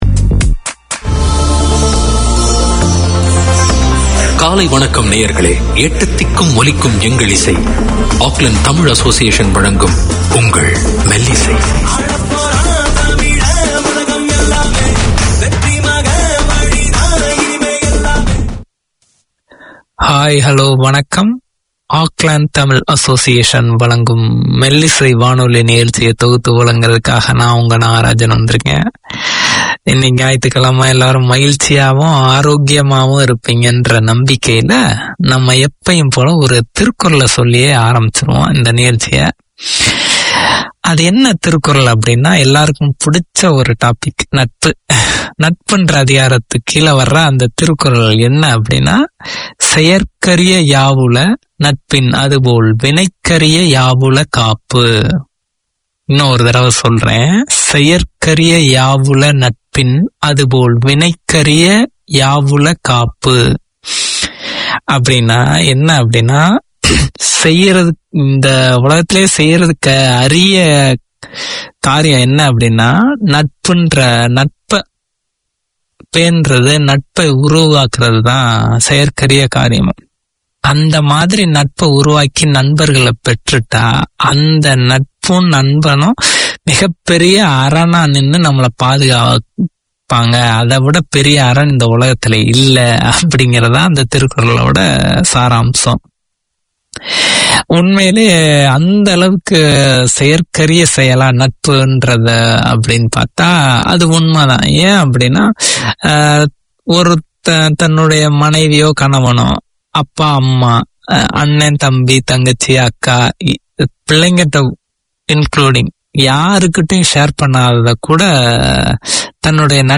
Radio made by over 100 Aucklanders addressing the diverse cultures and interests in 35 languages.
Tamil literature, poems, news and interviews - Melisai brings you current affairs, local and international events relevant to Tamils, with wit and humour. Each Sunday morning there’s the chance to hear local Tamil perspectives presented by Tamil speakers with a passion for the language and culture. The music is varied, the topics entertaining.